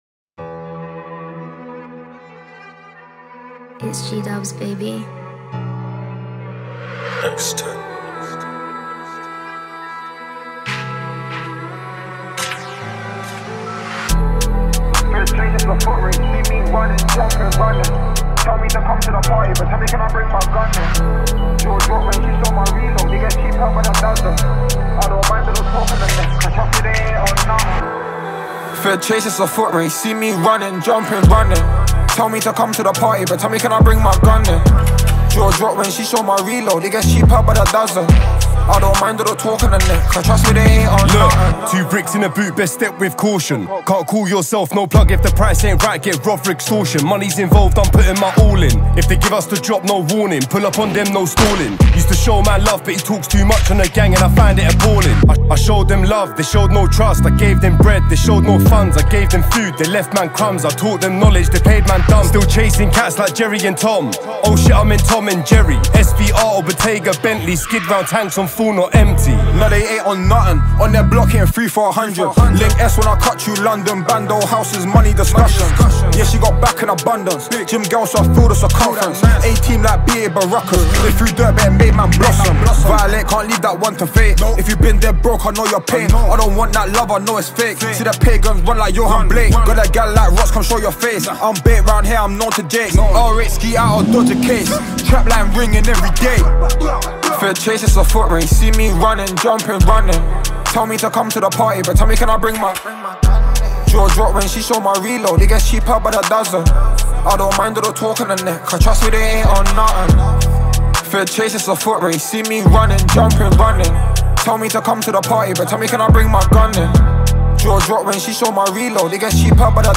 رپ جدید